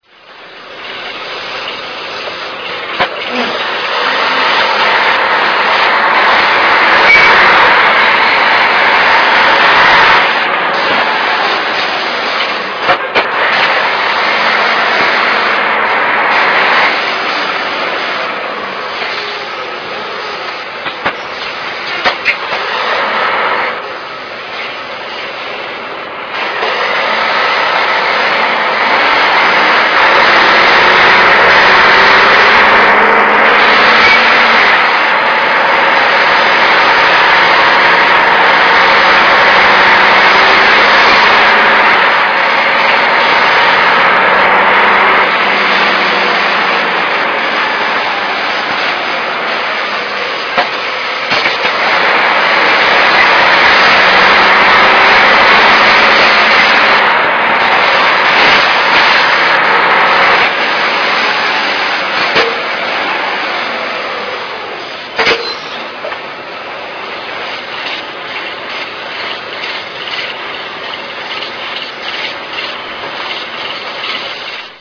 I was always interested in the 'Pneumocyclic' gearlever / gearbox in round buses, and really like the noise their engines make make better than any other buses' noises. (It might partly be because they are noisier than newer buses.)